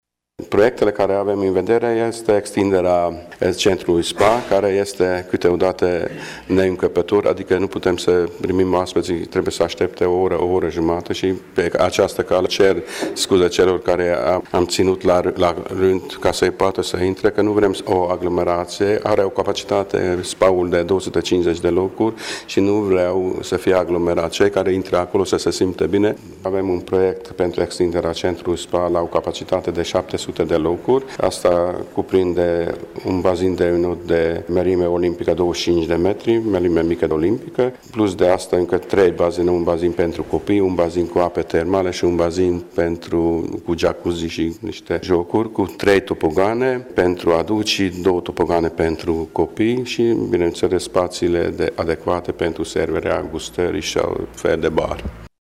Practic, actualul centru wellness de ultimă generație, dat în folosință la sfârșitul anului 2013, ar urma să-și tripleze capacitatea după finalizarea proiectelor de investiții avute în vedere de autorități, ne spune primarul Albert Tibor: